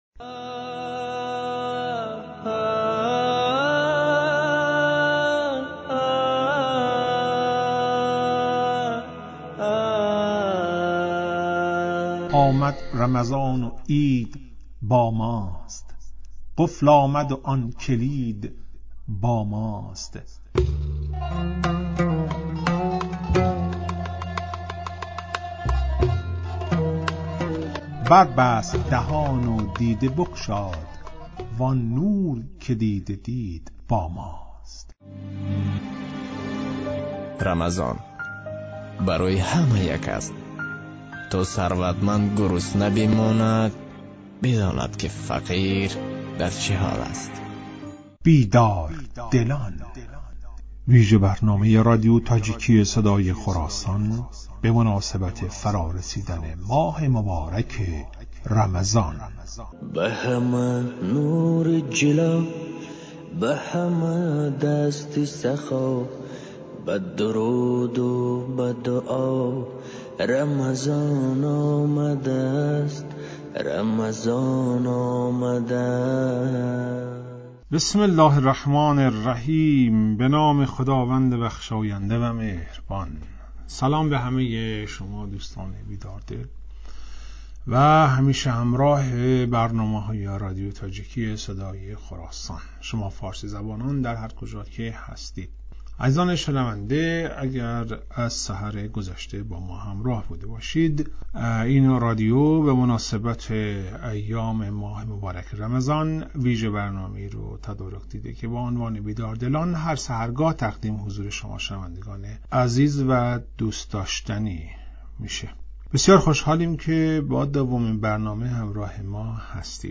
"بیدار دلان" ویژه برنامه ای است که به مناسبت ایام ماه مبارک رمضان در رادیو تاجیکی تهیه و پخش می شود.